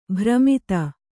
♪ bhramita